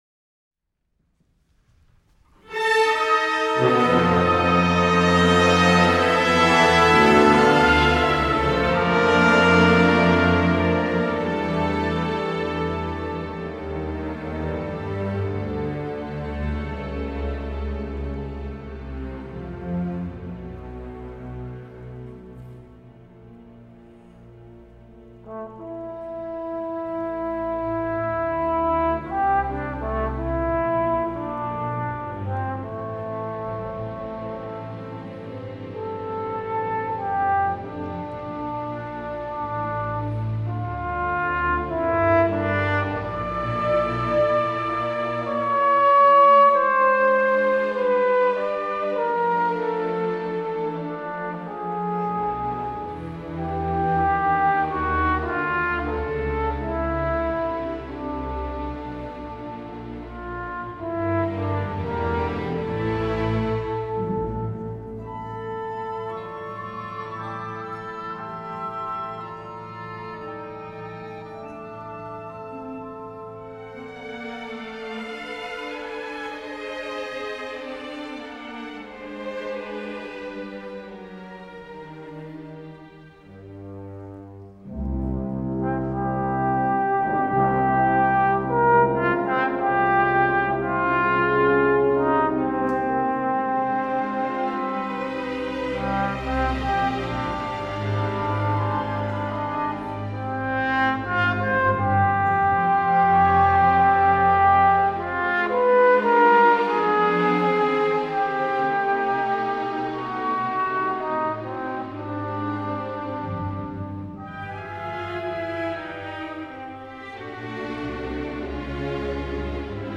From “Symphony for Trombone and Orchestra.